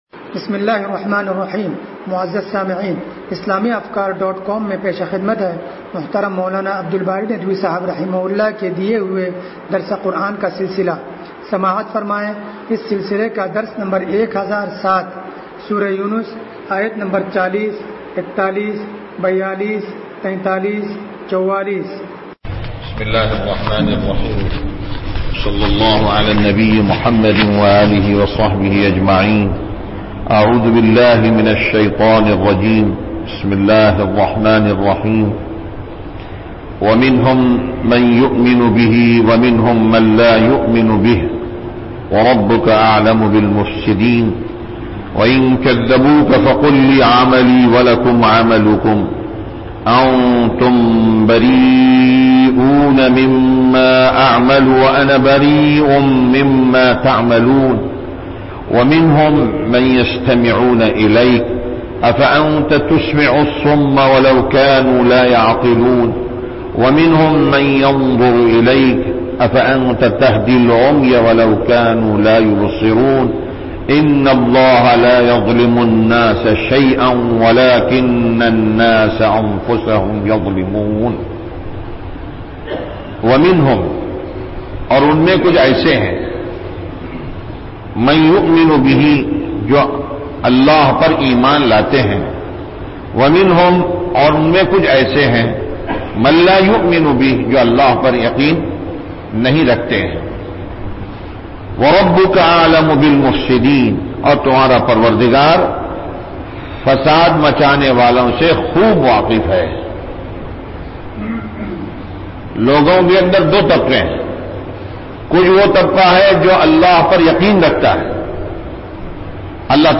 درس قرآن نمبر 1007
درس-قرآن-نمبر-1007.mp3